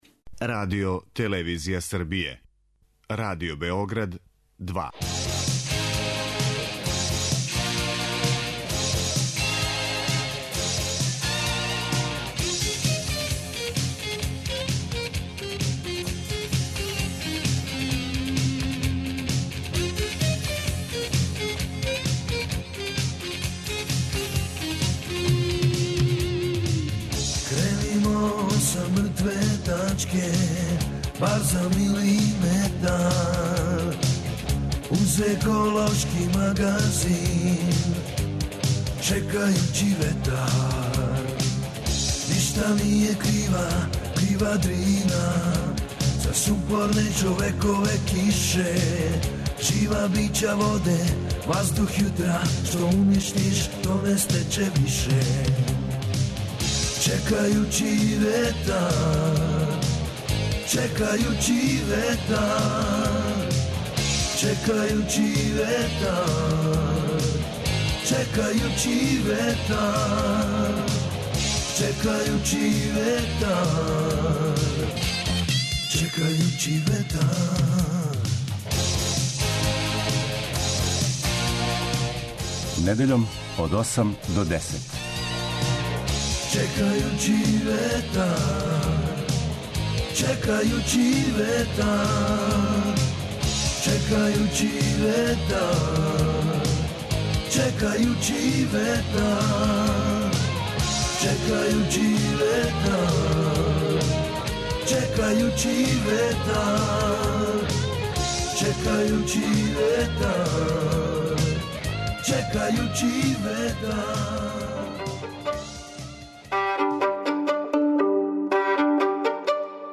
Чланови жирија су новинари еколошких рубрика штампаних гласила и електронских медија који су у претходном периоду кандидовали своје фаворите, а о најбољем кандидату са листе предлога данас ће се изјаснити путем телефонских укључења уживо, чиме ће се остварити пуна јавност рада жирија.